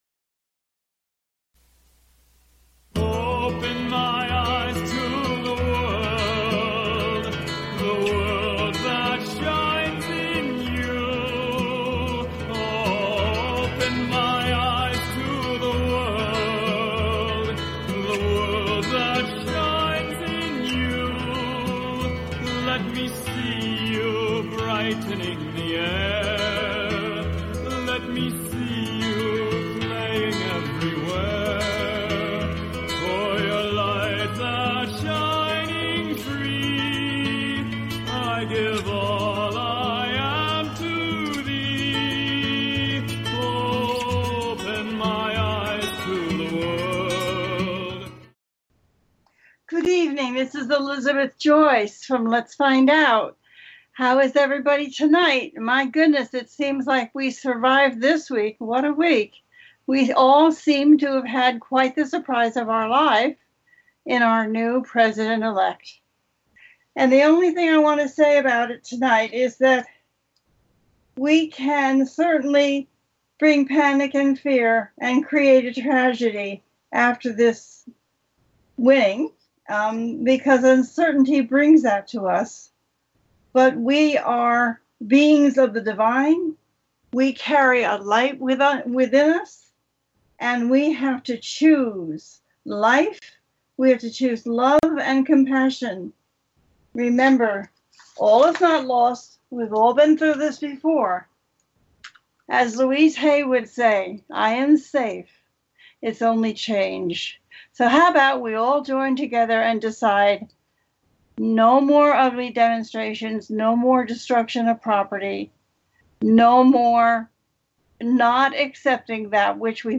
Let’s Find Out brings to its listeners illuminating and enthralling exploration of the connection between our minds and our bodies. This show brings a series of fascinating interviews with experts in the field of metaphysics.
The listener can call in to ask a question on the air.